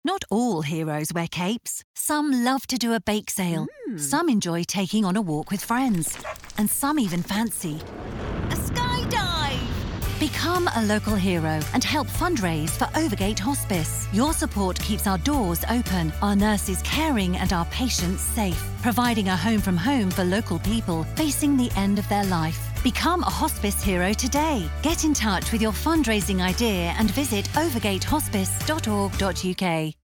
With my clear, bright British tones, I bring a touch of class to every project, ensuring your message resonates with listeners everywhere.
Words that describe my voice are British, Friendly, Natural.
0811Fun._Charity_Radio_Commercial.mp3